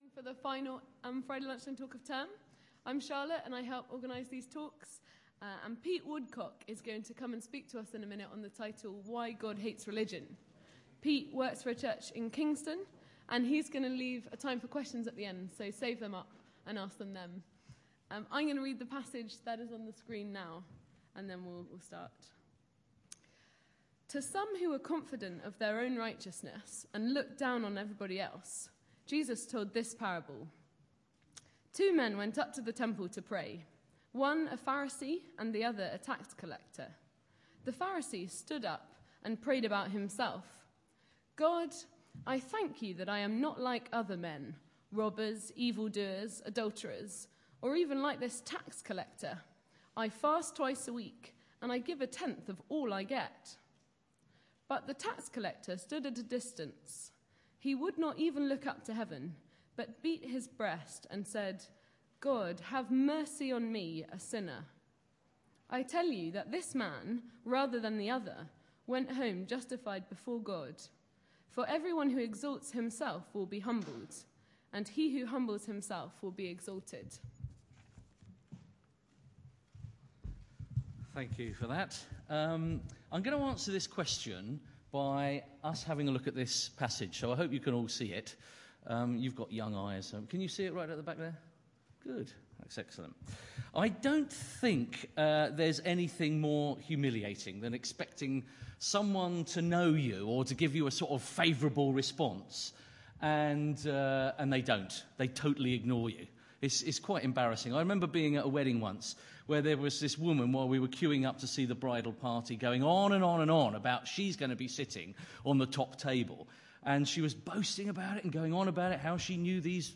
L09EA6 mpeg CICCU Media Type: Friday Lunchtime Talk Title: So much suffering: where is God when it hurts?